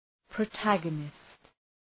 Προφορά
{prəʋ’tægənıst}